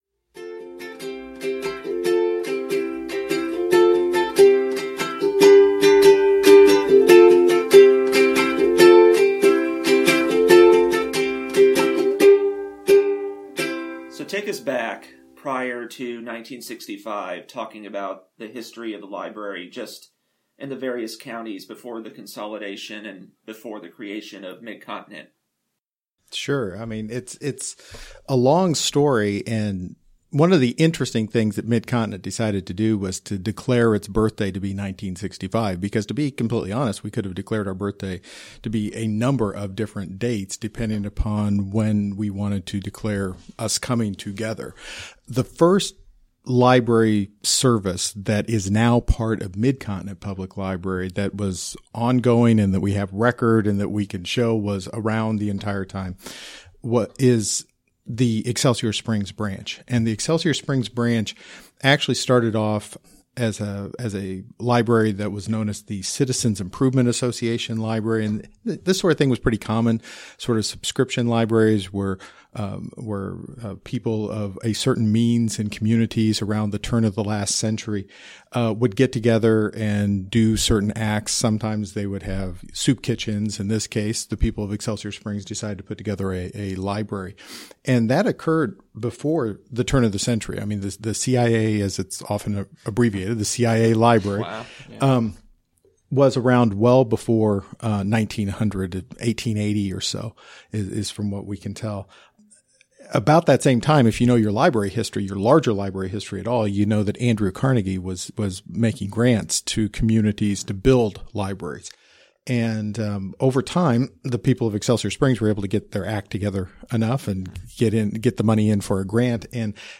For its 50th anniversary, a series of interviews were recorded detailing the story of Mid-Continent Public Library.